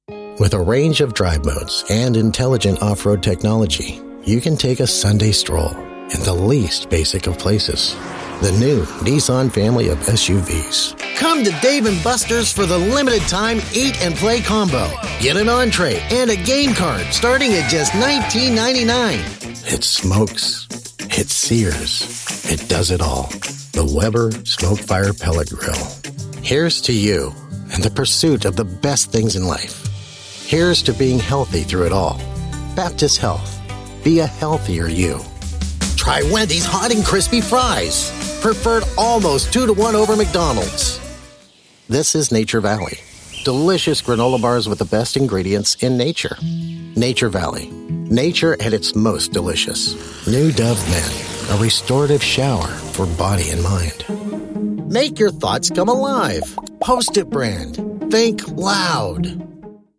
Male
Adult (30-50), Older Sound (50+)
Radio Commercials
Commercial Demo Of Versatility
All our voice actors have professional broadcast quality recording studios.